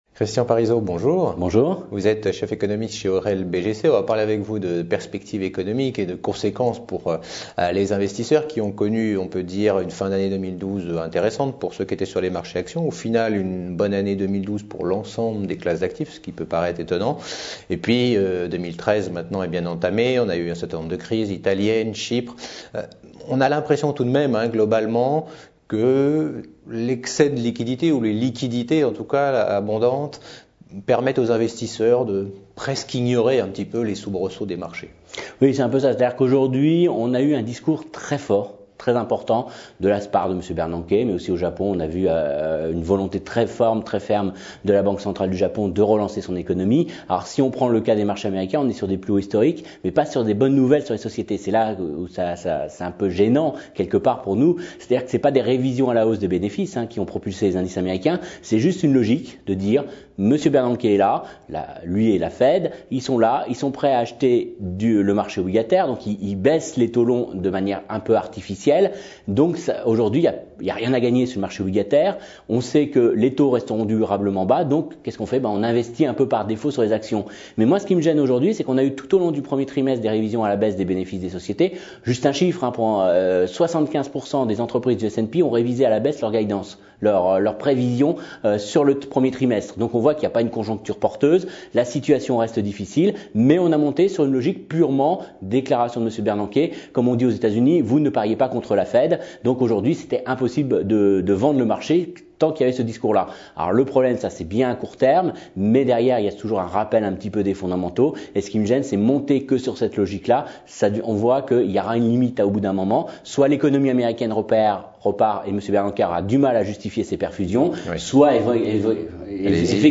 Economie et Marchés : Interview